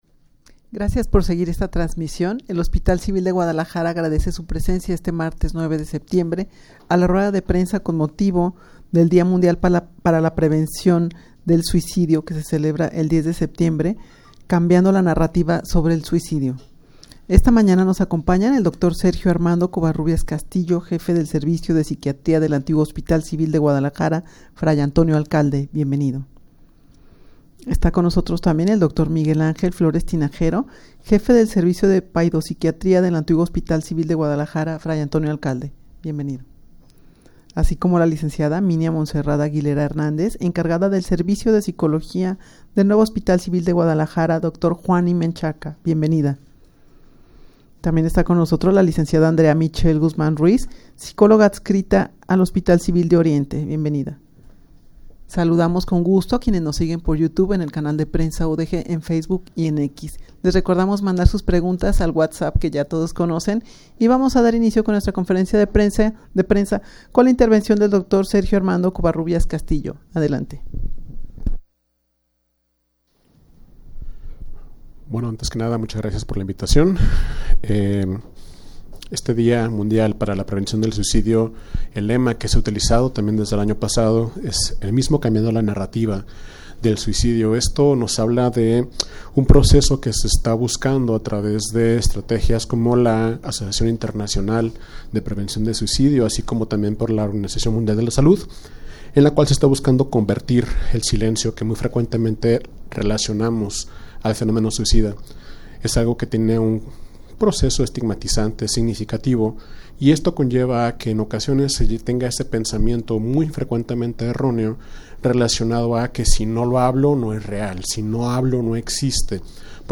Audio de la Rueda de Prensa
rueda-de-prensa-con-motivo-del-dia-mundial-para-la-prevencion-del-suicidio-cambiando-la-narrativa-sobre-el-suicidio.mp3